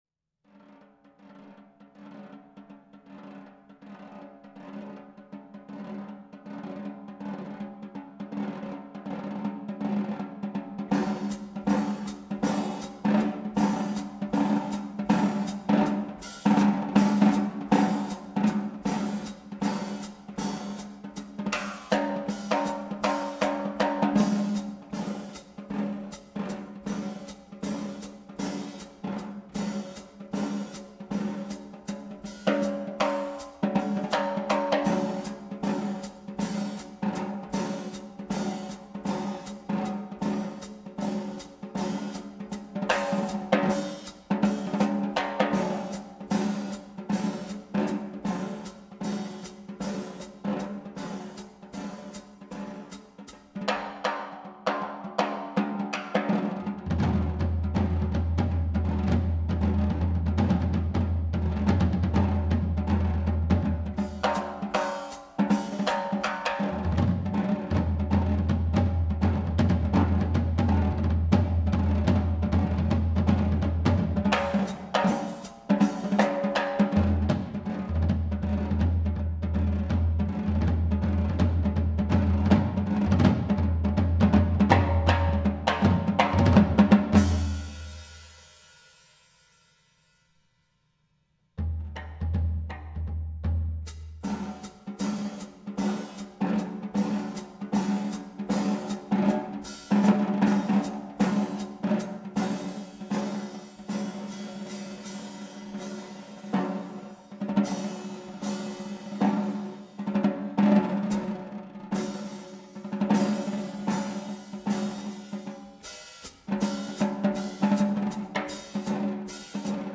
Voicing: Multi-Percussion Unaccompanied